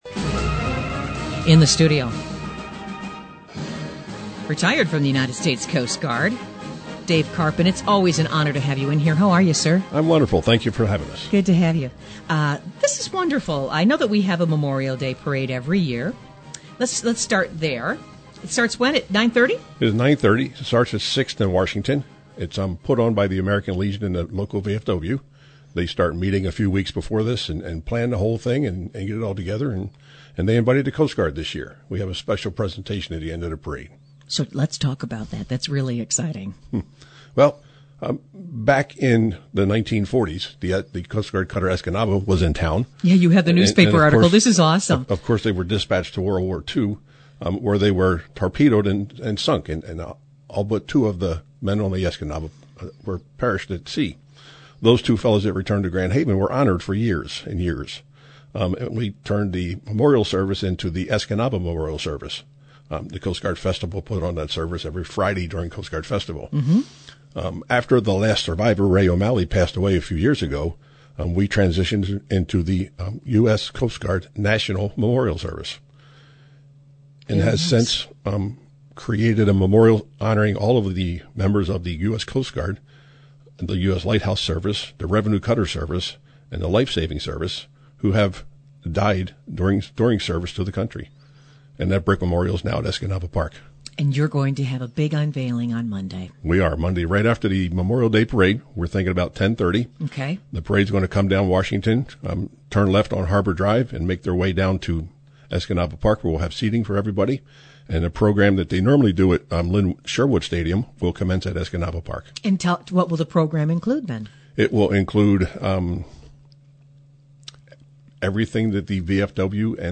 in the WGHN studios